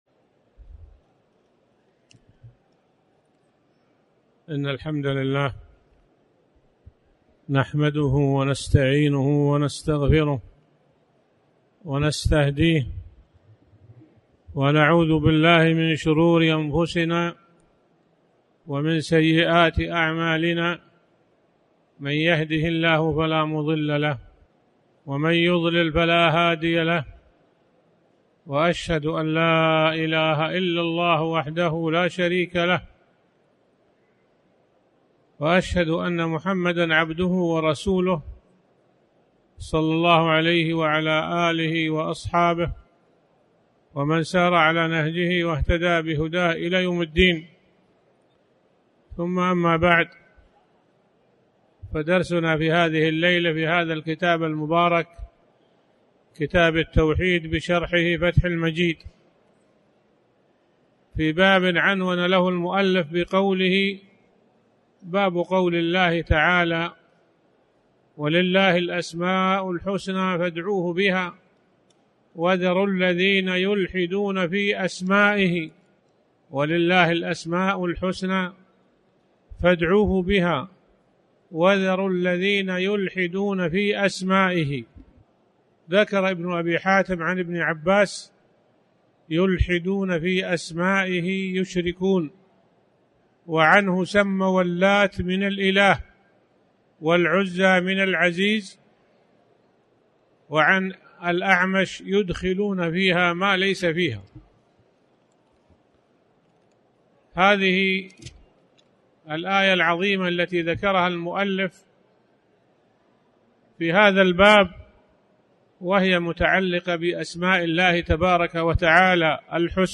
تاريخ النشر ٤ ذو القعدة ١٤٣٩ هـ المكان: المسجد الحرام الشيخ